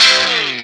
Track 02 - Guitar Stab OS 05.wav